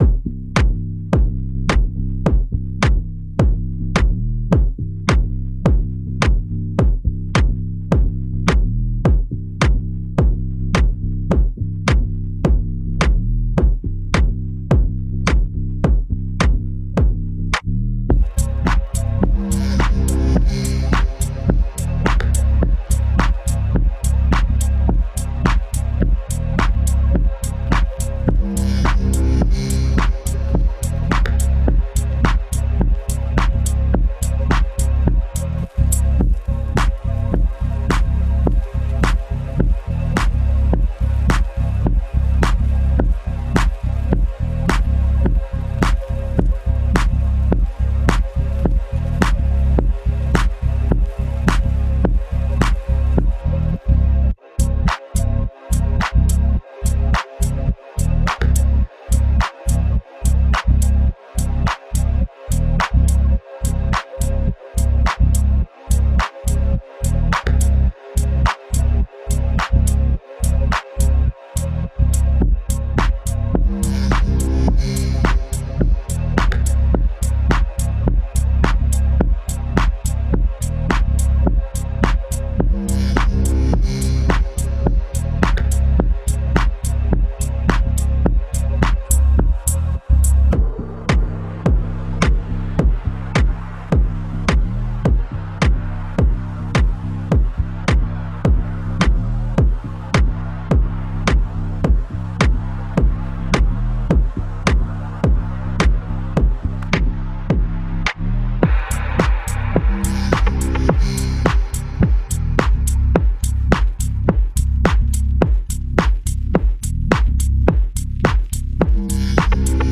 House Music, Deep House